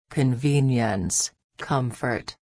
(kəˈmɒdɪti)   mercadoria, artigo comodidade convenience, comfort